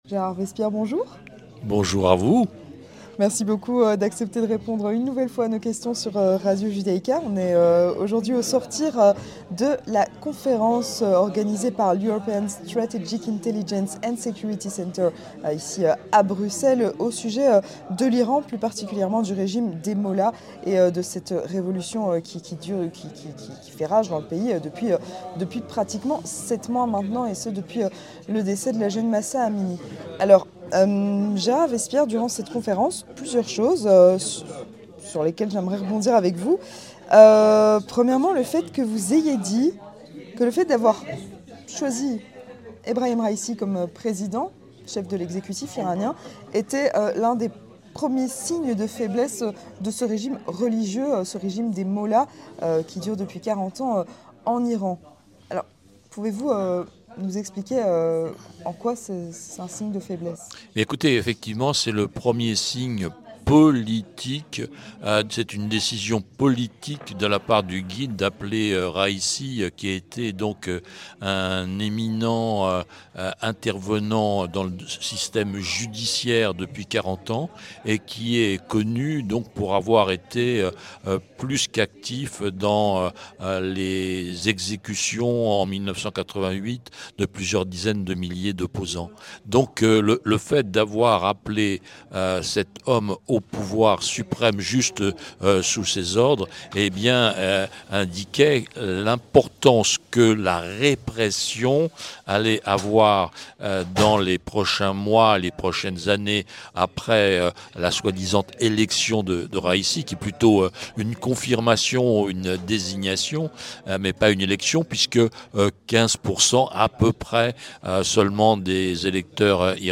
Entretien du 18h - Conférence sur l'Iran organisée par le European Strategic Intelligence and Security Center